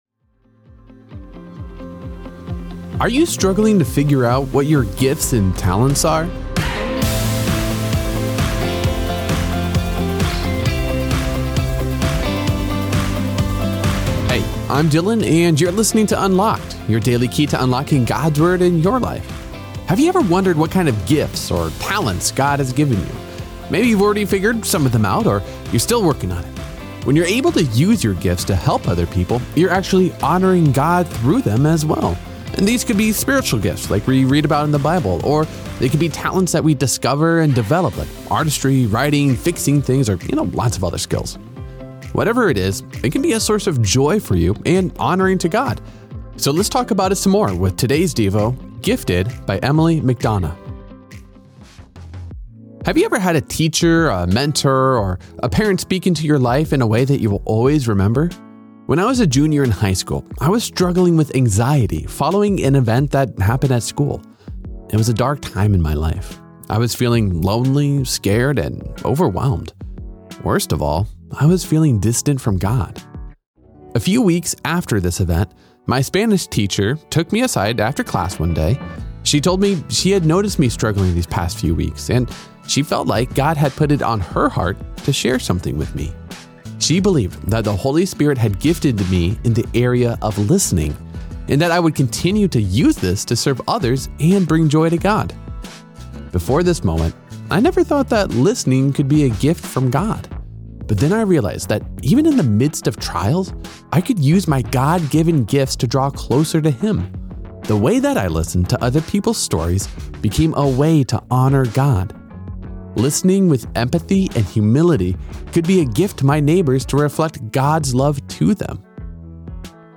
Unlocked is a daily teen devotional, centered on God’s Word. Each day’s devotion—whether fiction, poetry, or essay—asks the question: How does Jesus and what He did affect today’s topic?